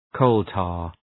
Προφορά
{‘kəʋltɑ:r}